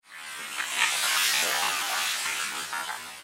Звук перемещения вируса Covid-19 в теле человека